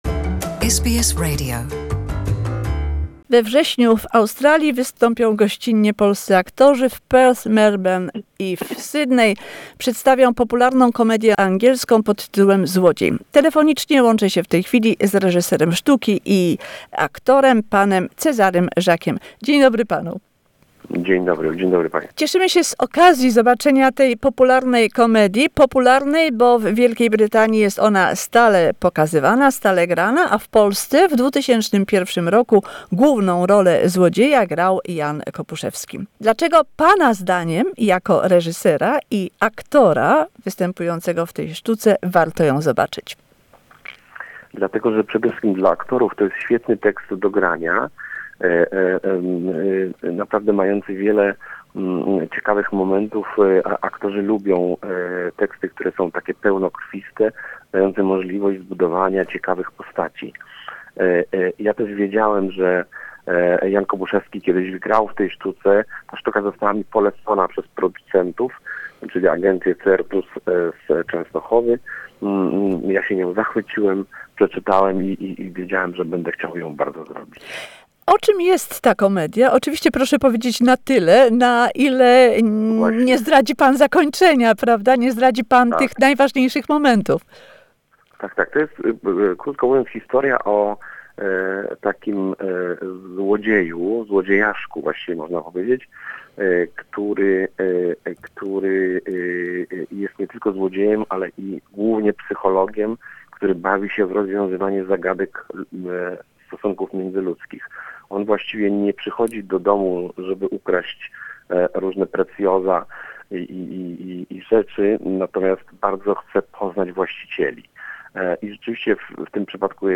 ...Polonię z Perth, Melbourne i Sydney na przedstawienie komedii „ Złodziej”. Rozmowa z reżyserem i odtwórcą roli Złodzieja Cezarym Żakiem.